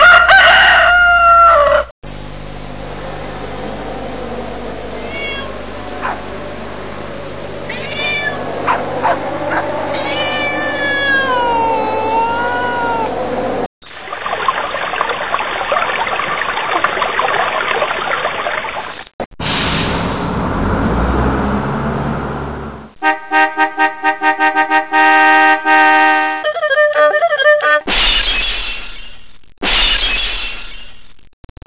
各种声音